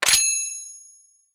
TP_GaleBoomerang_Lock3.wav